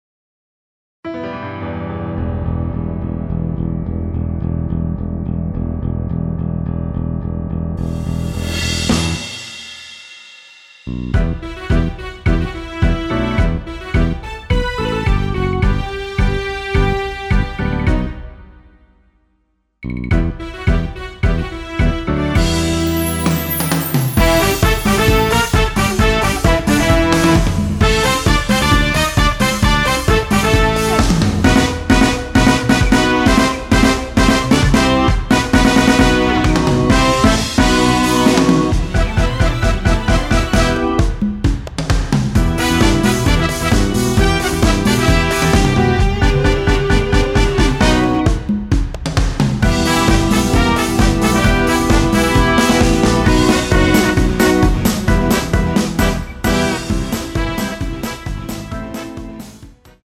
Em
◈ 곡명 옆 (-1)은 반음 내림, (+1)은 반음 올림 입니다.
앞부분30초, 뒷부분30초씩 편집해서 올려 드리고 있습니다.
중간에 음이 끈어지고 다시 나오는 이유는